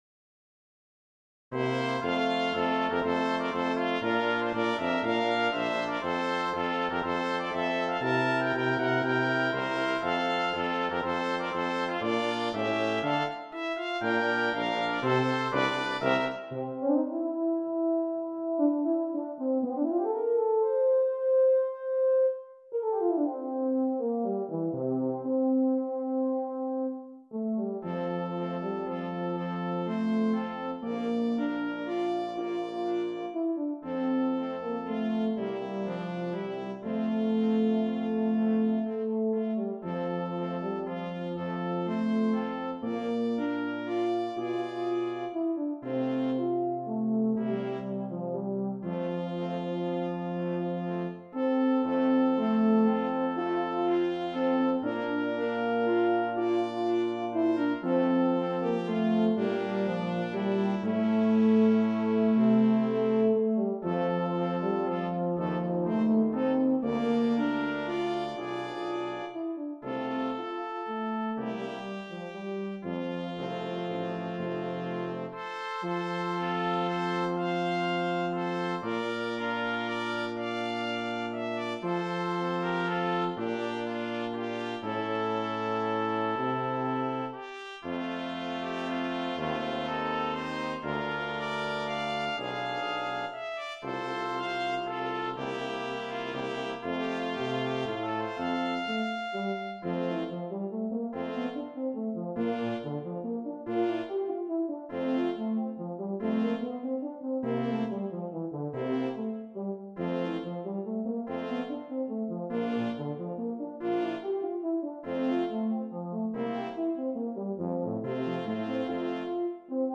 Voicing: Euphonium Solo